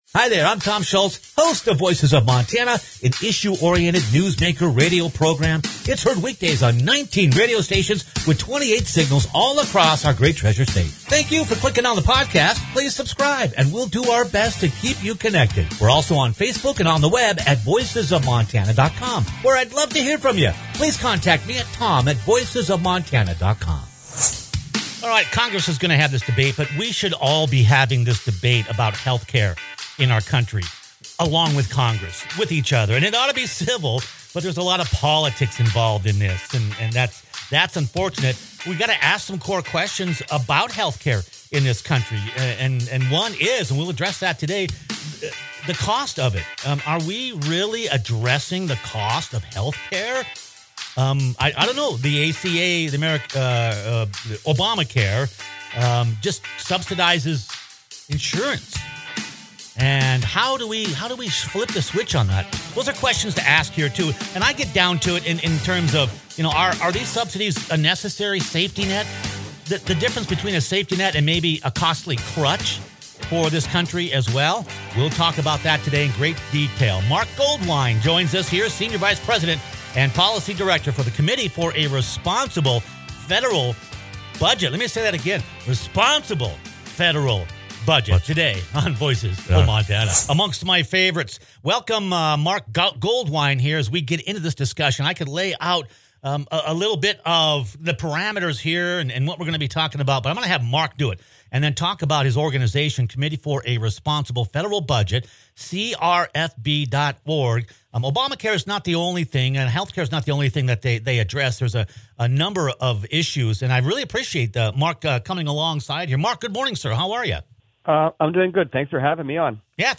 conversation about healthcare